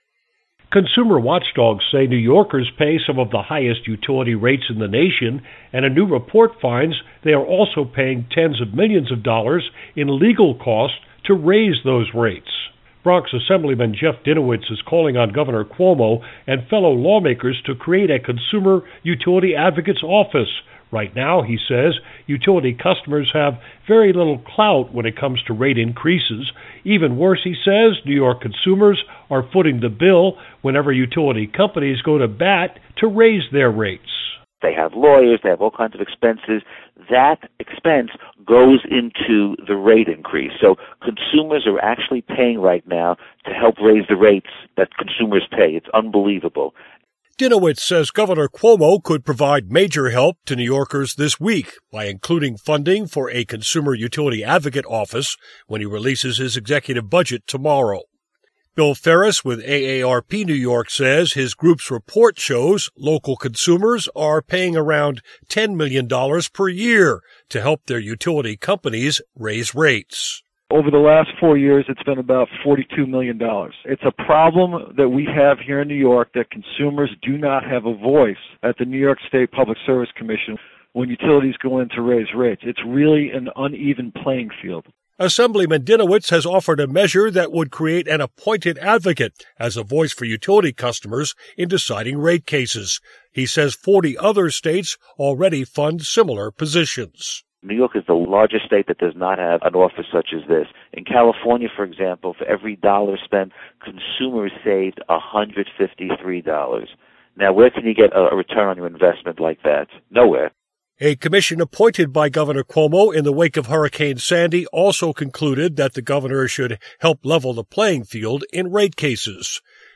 Report: NY'ers pay to help utilities raise rates